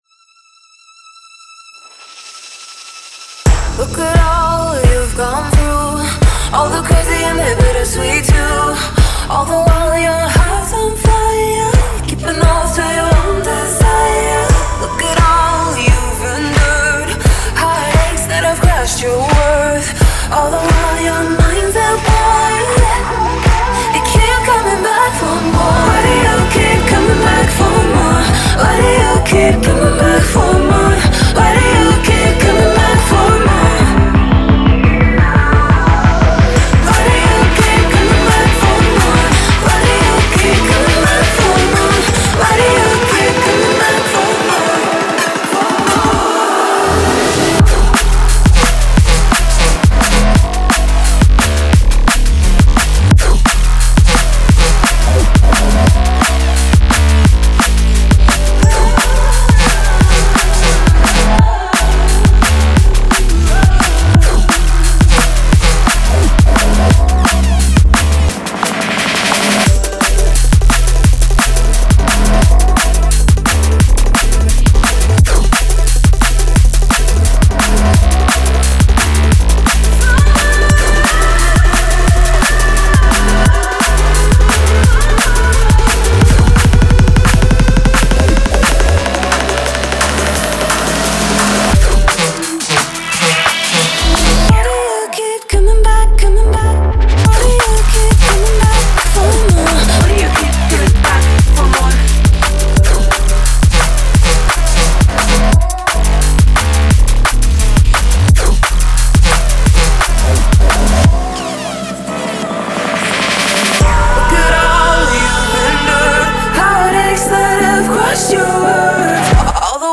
Neurofunk, Drum & bass, Dark, Heavy, Suspense & Chasing